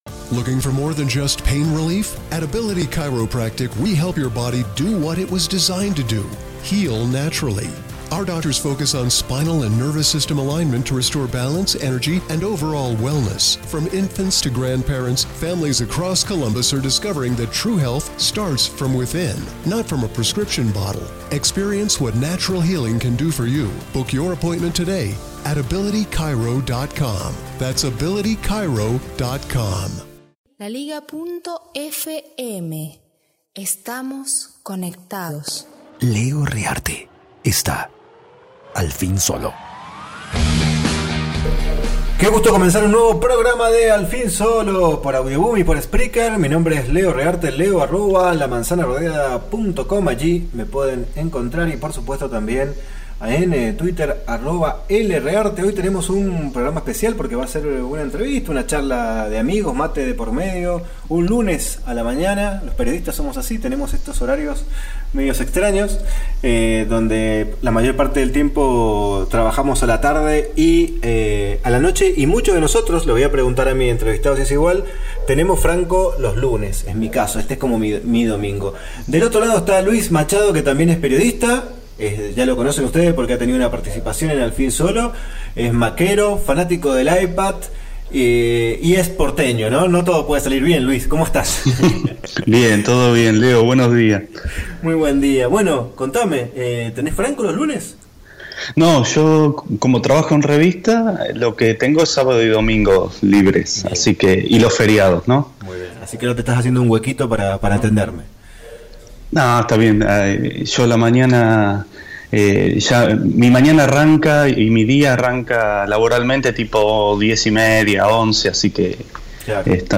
Todo sobre el iPad Pro: entrevista